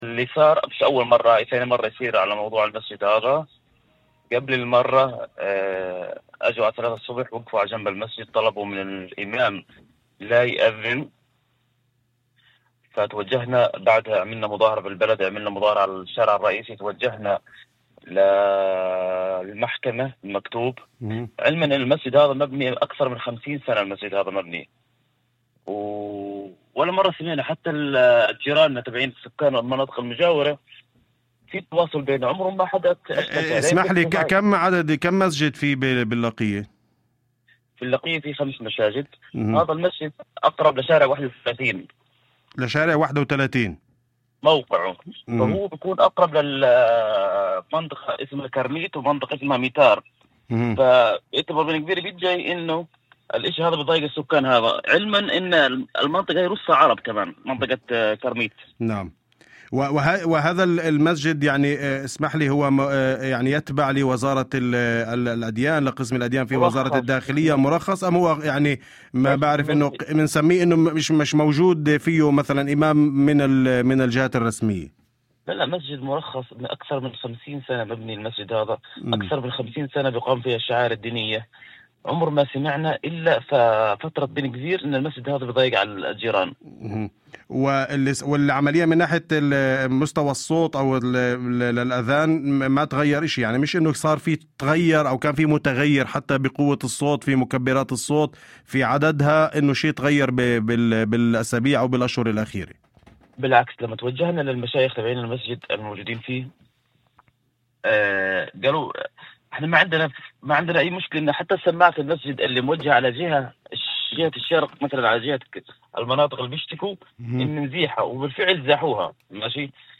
وقال شريف الأسد، رئيس مجلس اللقية المحلي، إن ما جرى ليس المرة الأولى، موضحا أن المسجد قائم ومرخص منذ أكثر من خمسين عاما دون أي شكاوى سابقة.
وأضاف في مداخلة هاتفية لبرنامج "أول خبر"، على إذاعة الشمس: "الشرطة حضرت فجرا في إحدى المرات وطلبت من الإمام عدم رفع الأذان، وبعدها خرجنا في مظاهرة داخل البلدة وعلى الشارع الرئيسي وتوجهنا إلى المحكمة".